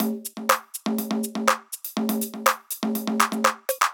ORG Beat - Perc Mix 2.wav